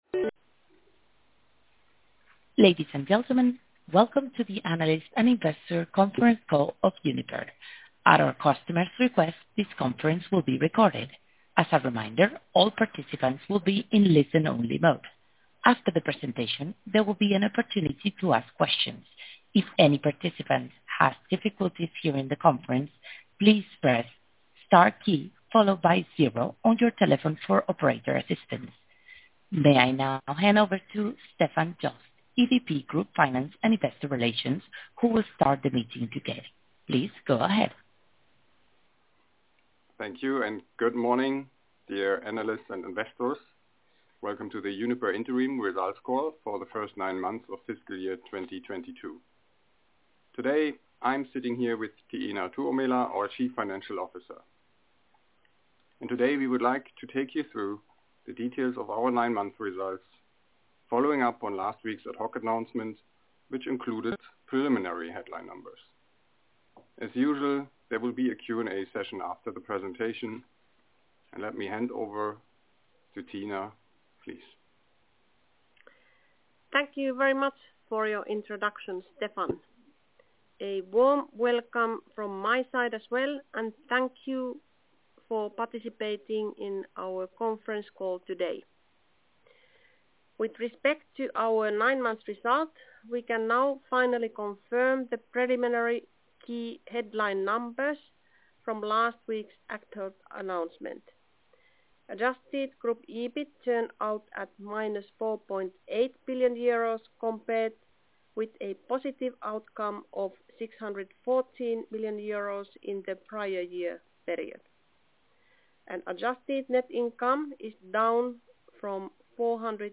Uniper_9M_2022_Conference_Call.mp3